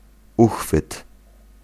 Ääntäminen
Synonyymit anse oreille quarteron Ääntäminen France: IPA: /pwa.ɲe/ Haettu sana löytyi näillä lähdekielillä: ranska Käännös Ääninäyte 1. uchwyt {m} 2. rączka {f} 3. garść {f} 4. jelec 5. garstka {f} Suku: f .